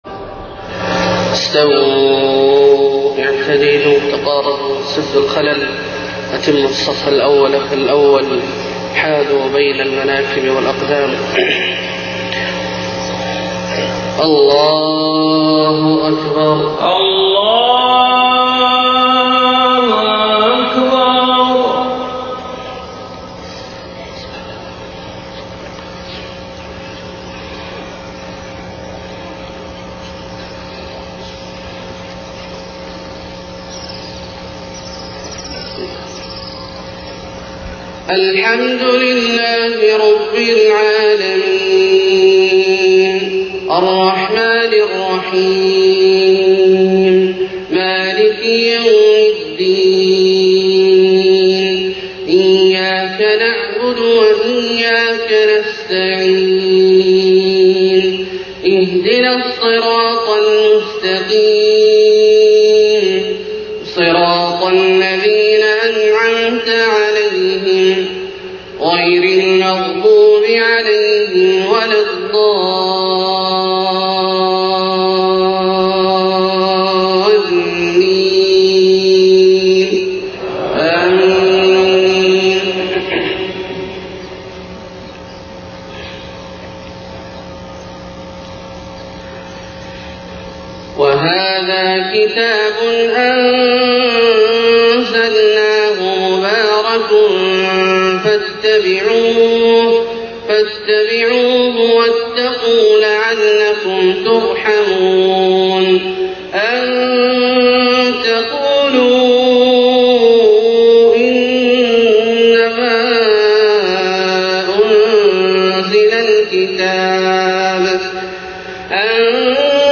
صلاة الفجر 3 صفر 1430هـ خواتيم سورة الانعام 155-165 > 1430 🕋 > الفروض - تلاوات الحرمين